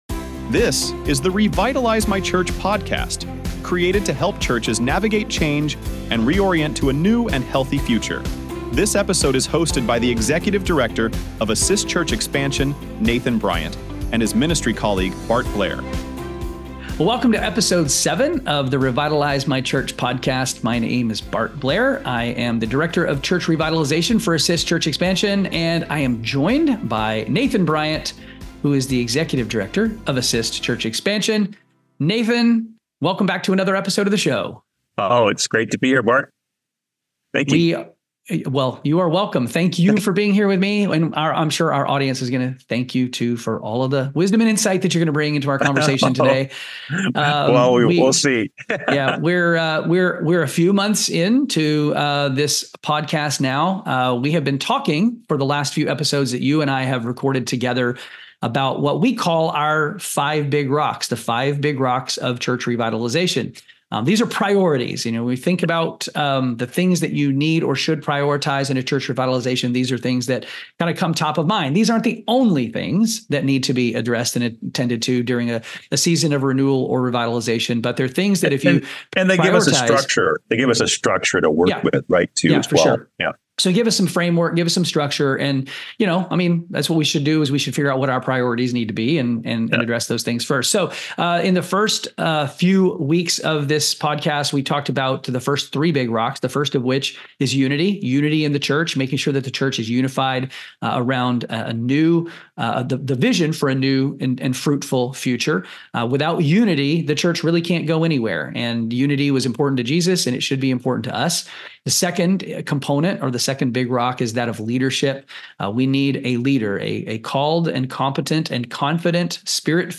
This topic is the fourth of their "five big rocks" of church revitalization, following unity, leadership, and team. The conversation begins by addressing the common objection that strategic planning is too "business-like" for the church.